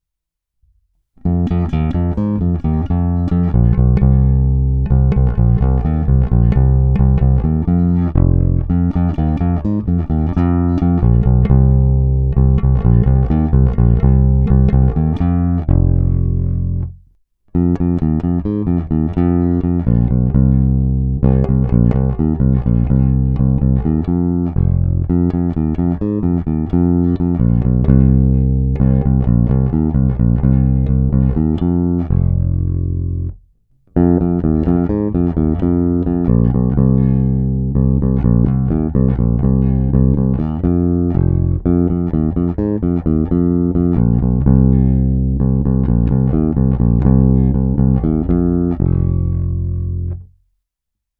Zvuk je typický Precision.
Není-li uvedeno jinak, následující nahrávky jsou vyvedeny rovnou do zvukové karty, vždy s plně otevřenou tónovou clonou, a kromě normalizace ponechány bez úprav.
Hra mezi snímačem a kobylkou